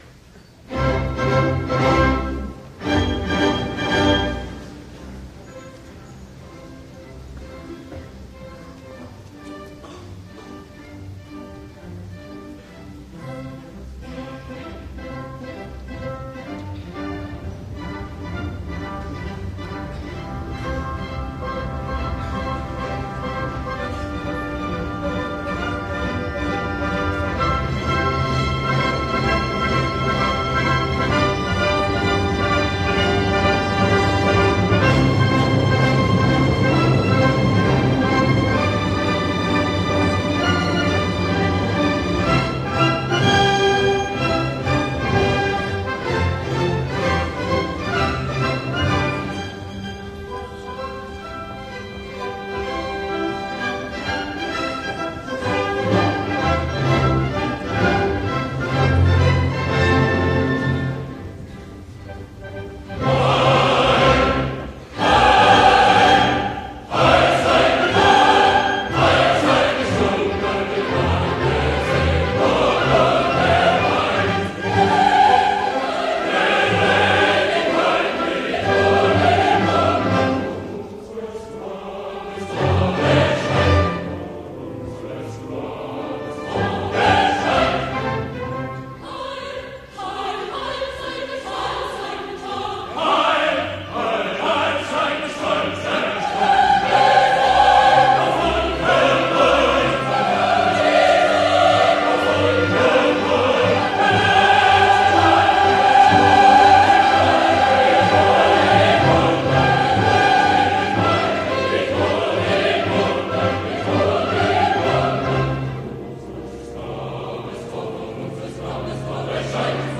音乐 刚毅豪迈，气魄雄伟，具有哲理性、戏剧性。
合唱（守卫士兵；囚犯；群众）